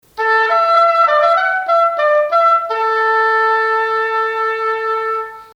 Oboe
Schön – warm – der Singstimme sehr ähnlich!
Der Ton wird erzeugt, indem die Luft zwischen den beiden Rohrblättern hindurch gepresst wird.
Oboe.mp3